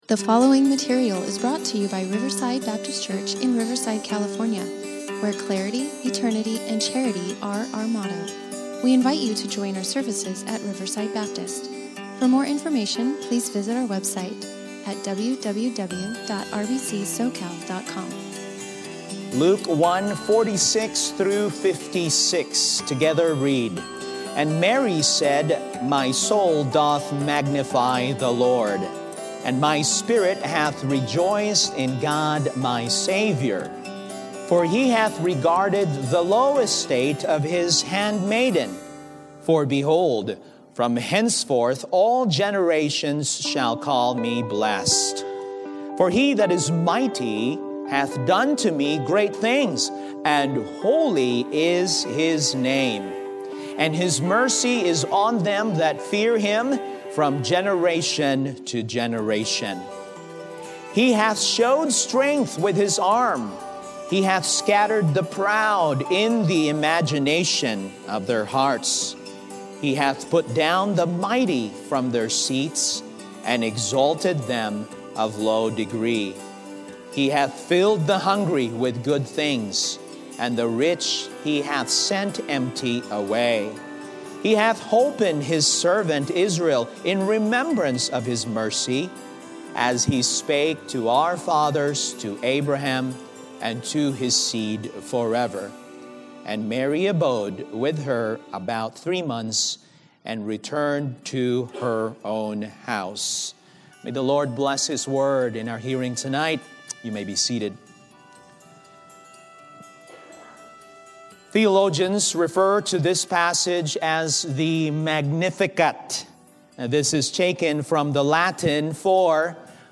Luke 1:46-56 Service Type: Sunday Evening Worship Mary’s Song